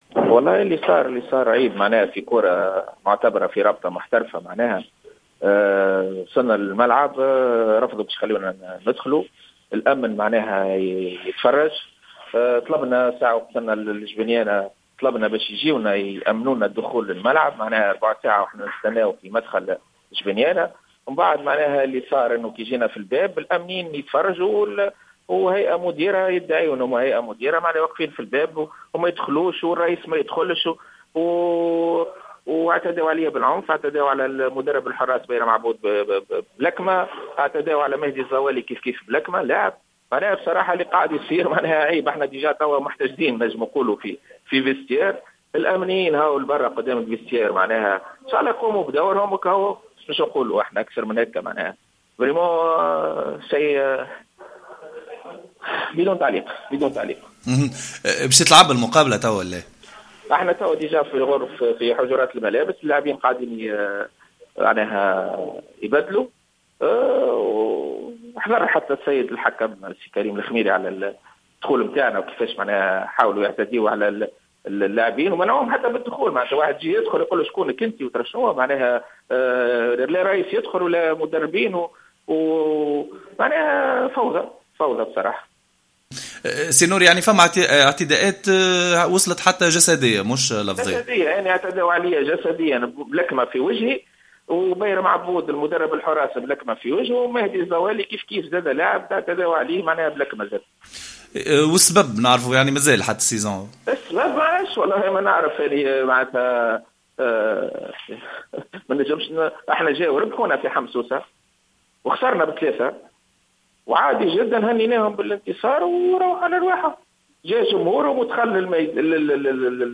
تصريح للجوهرة أف ام